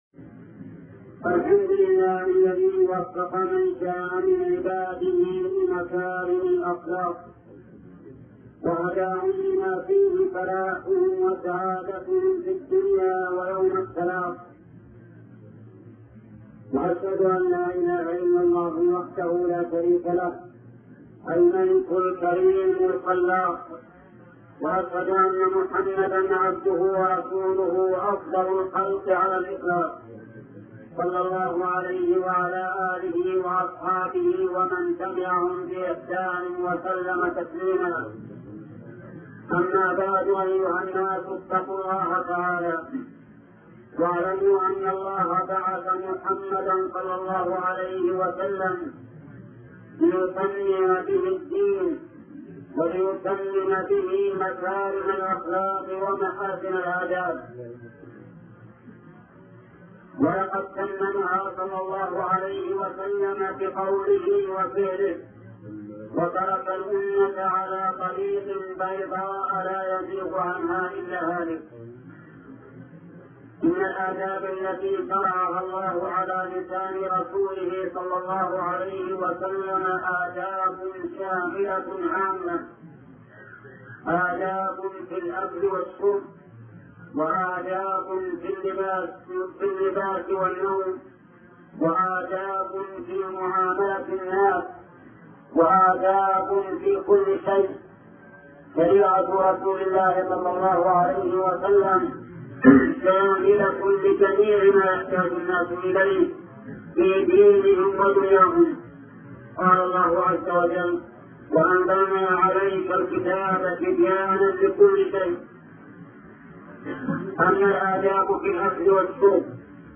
شبكة المعرفة الإسلامية | الدروس | بر الوالدين |محمد بن صالح العثيمين